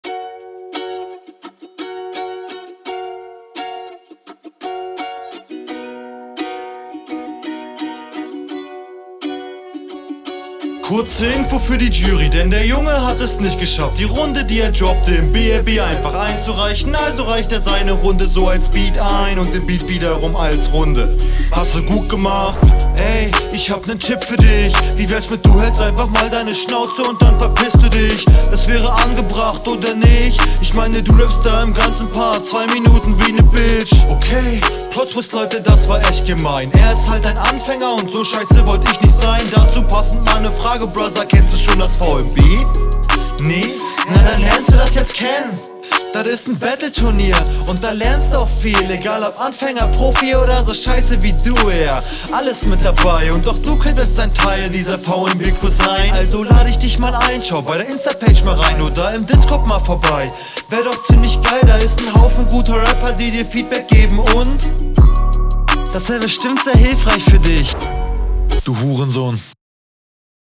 Viel viel geiler geflowt als der Gegner, vielleicht sogar besser als auf deinen eigenen Beat. …